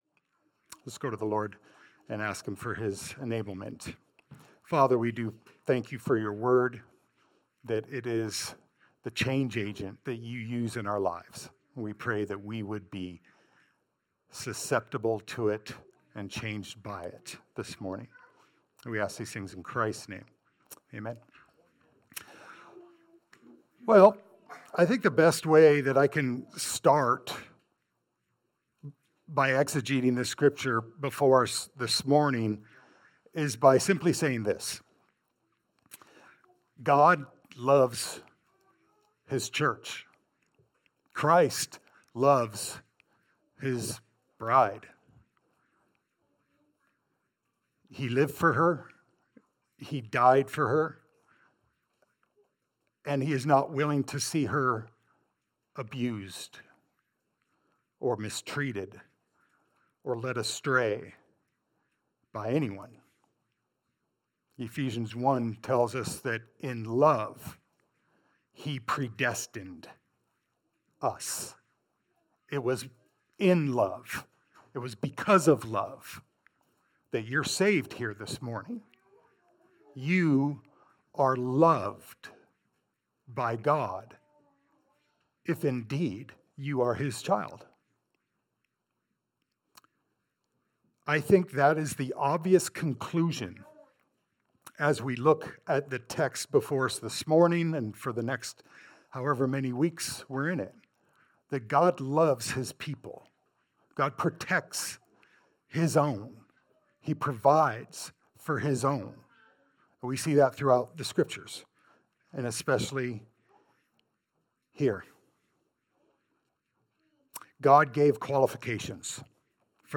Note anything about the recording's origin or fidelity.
Titus 1:6a Service Type: Sunday Service « “Put Things In Order” “Pastoral Qualifications